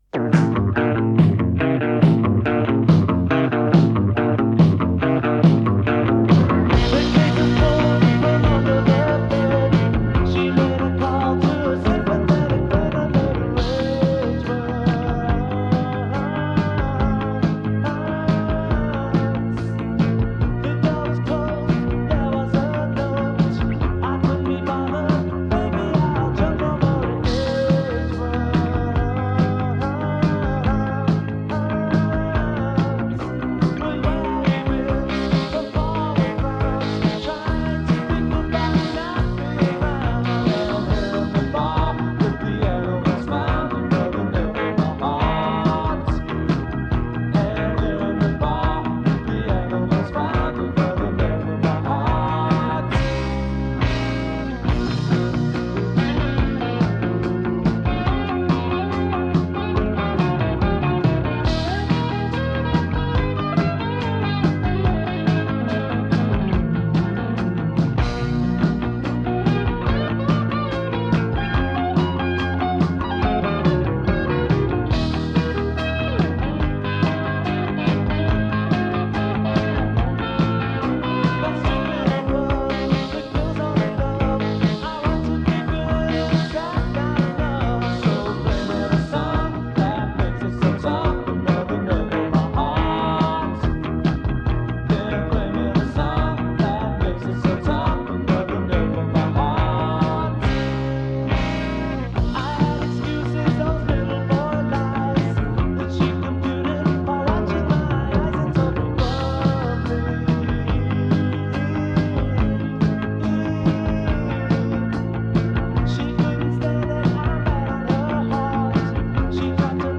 Am I the only one who recorded it off the radio?
I love the bass and the live feel to the recordings.